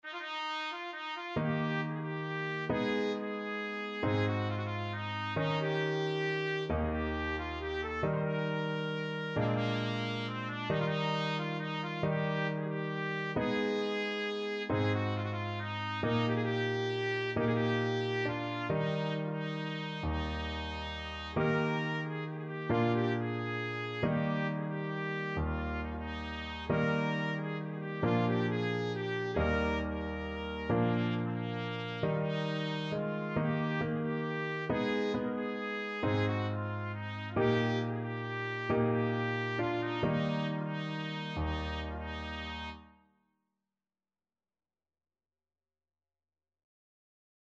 World Trad. Joc In Patru (Romanian Folk Song) Trumpet version
Eb major (Sounding Pitch) F major (Trumpet in Bb) (View more Eb major Music for Trumpet )
3/4 (View more 3/4 Music)
One in a bar .=45
romania_joc_in_patru_TPT.mp3